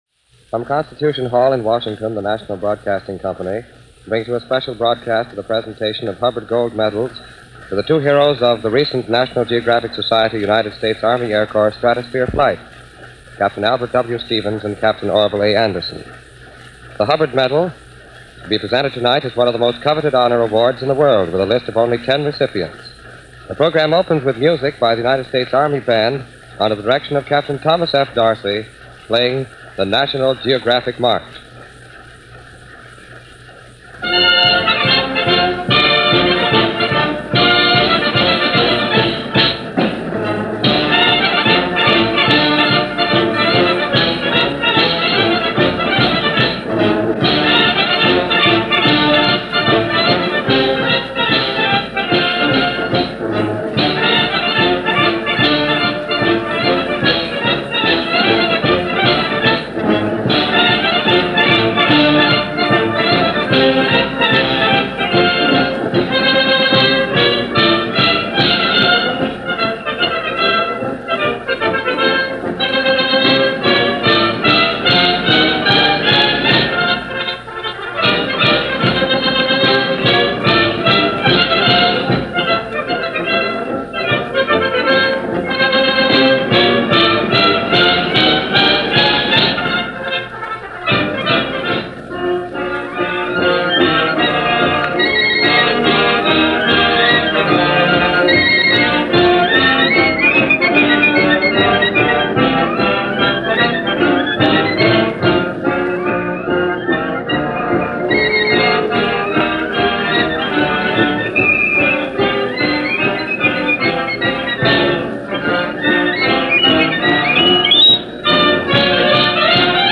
NBC Radio